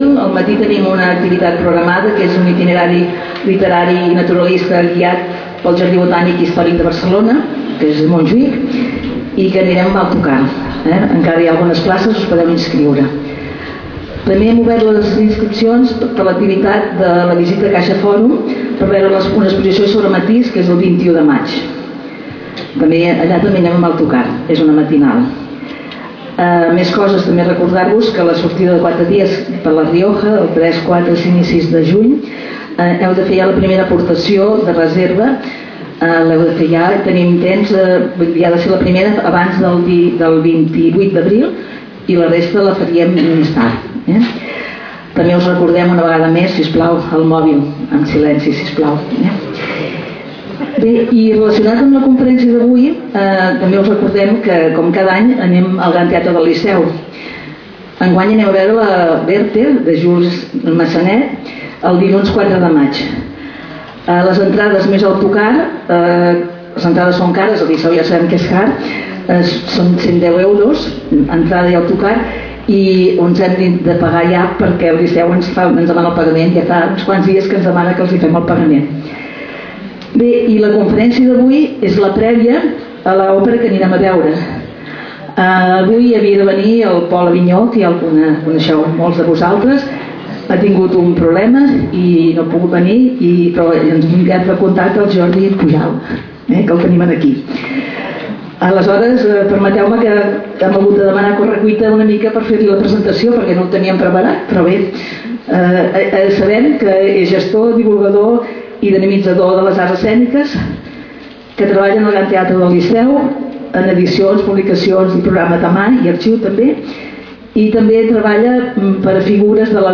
Lloc: Casal de Joventut Seràfica
Conferències Notícies Cada any